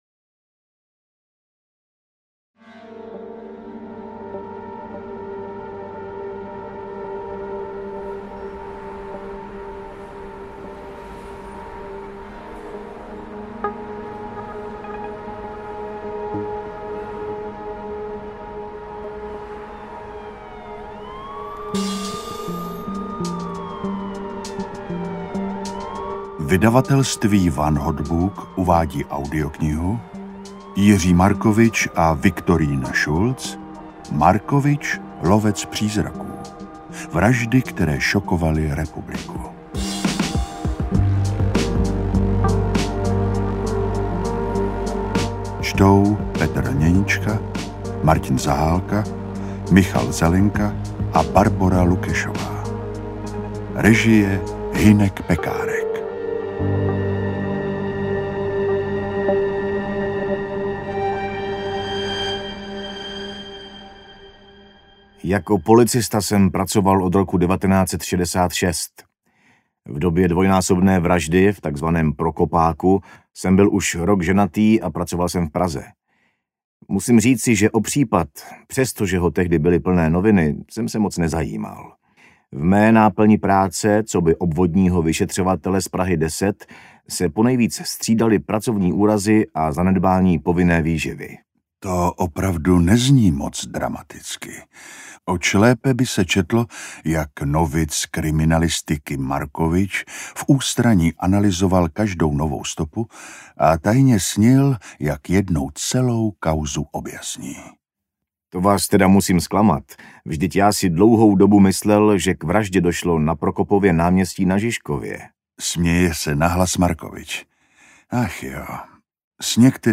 Markovič: Lovec přízraků audiokniha
Ukázka z knihy
markovic-lovec-prizraku-audiokniha